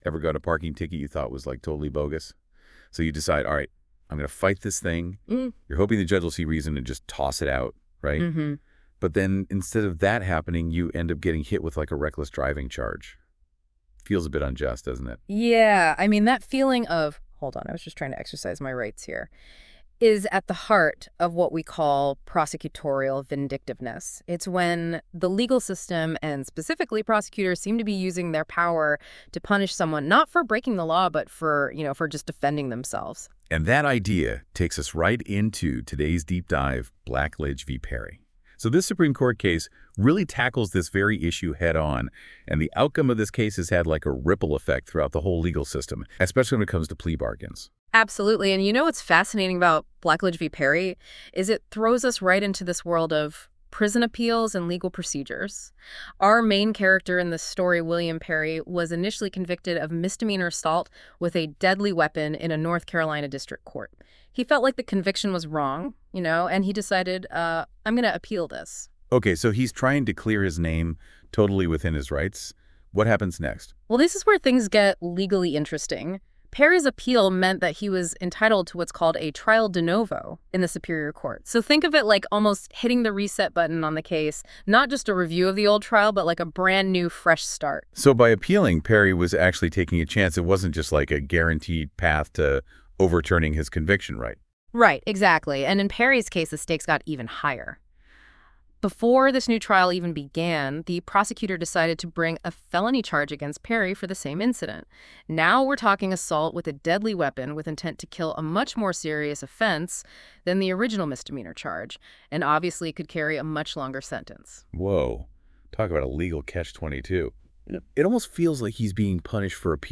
Listen to an audio breakdown of Blackledge v. Perry.